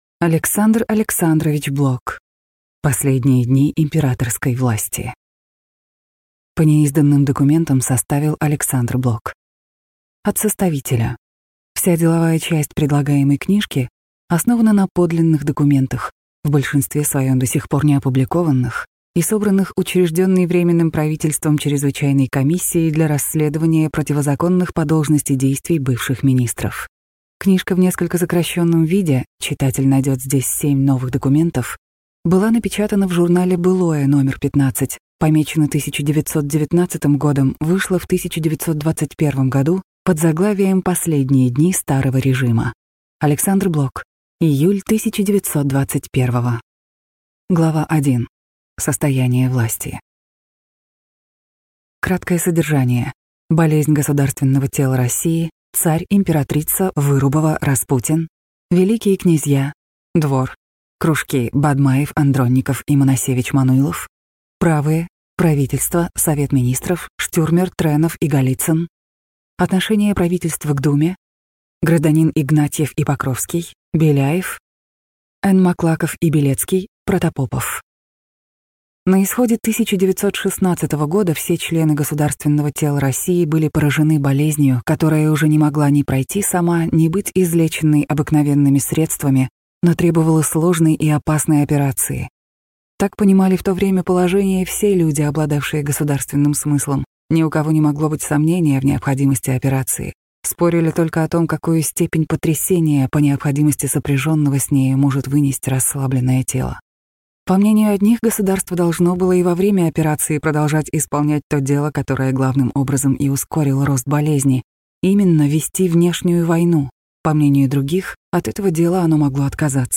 Аудиокнига Последние дни императорской власти | Библиотека аудиокниг